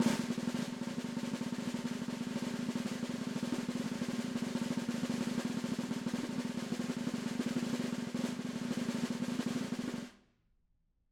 Snare2-rollSN_v3_rr1_Sum.wav